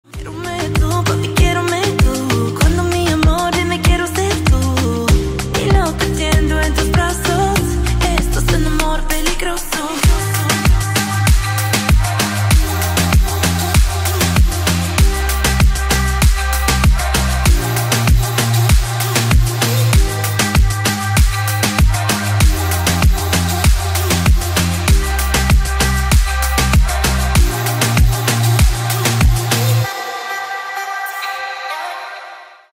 Латинские Рингтоны
Поп Рингтоны